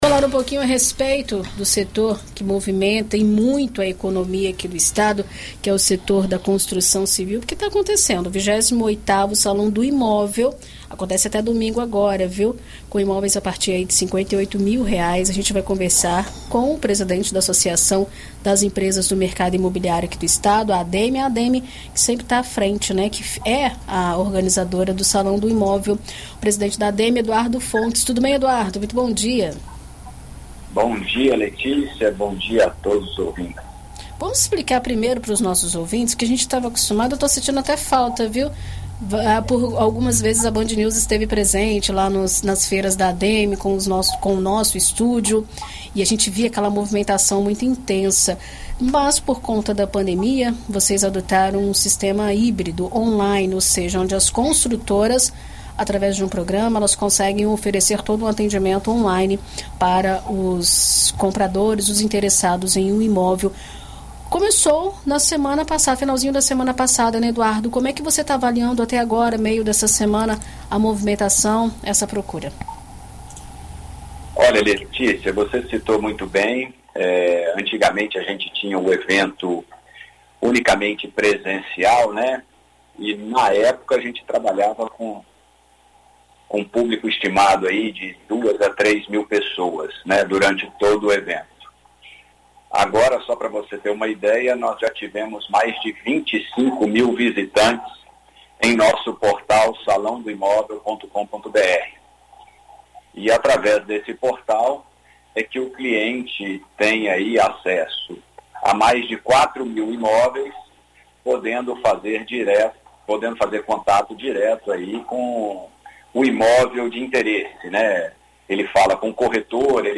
Em entrevista à BandNews FM Espírito Santo nesta quarta-feira (04)